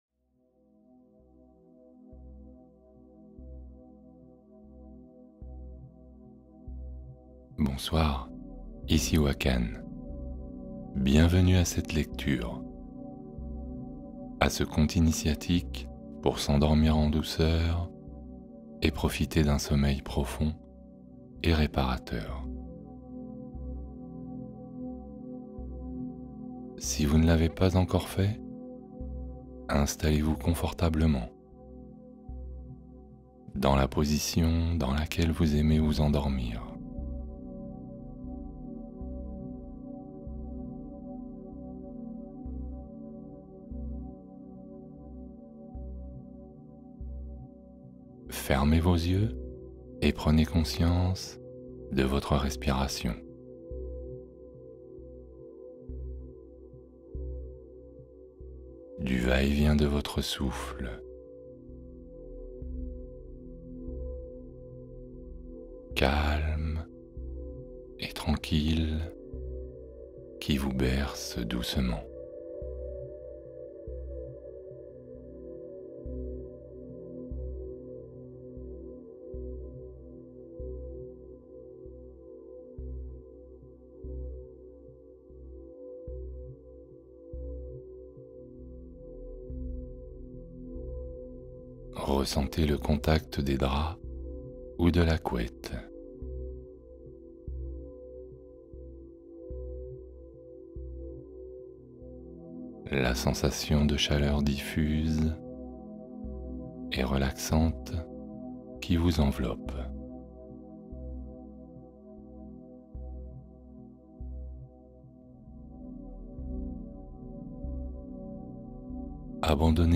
Conte pour dormir : voyage apaisant vers un sommeil réparateur